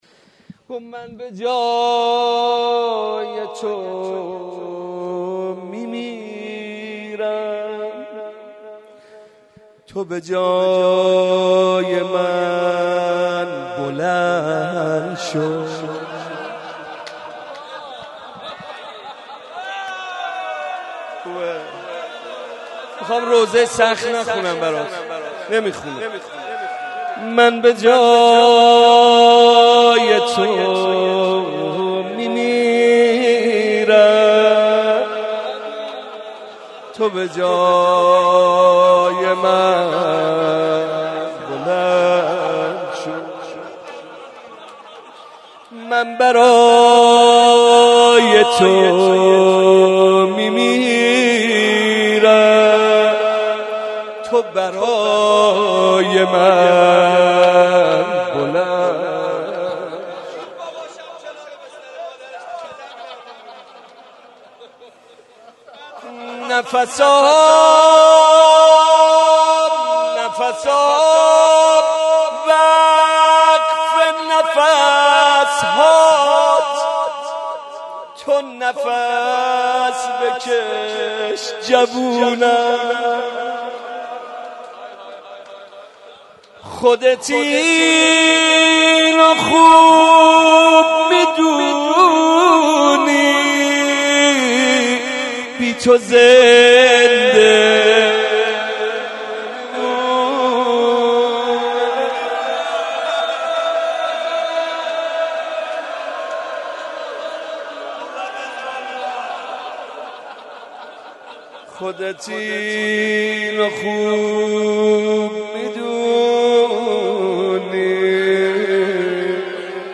02.rozeh1.mp3